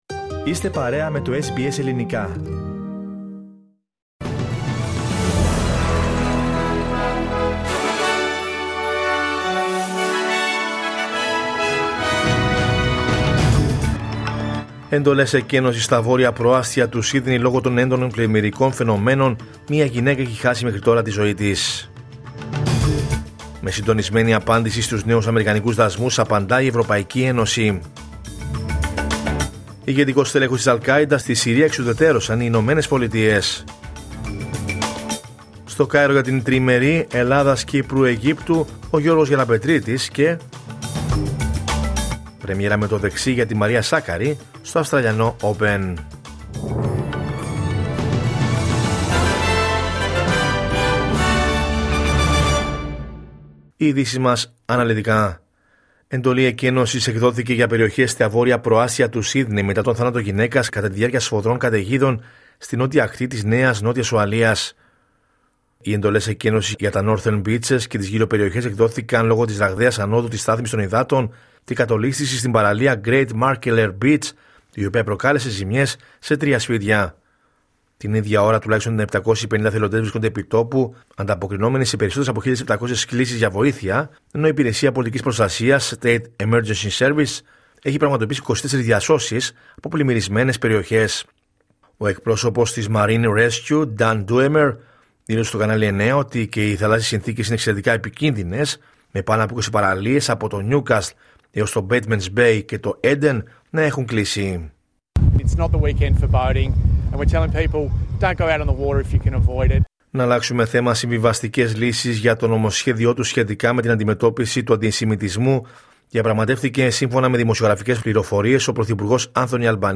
Ειδήσεις: Κυριακή 18 Ιανουαρίου 2026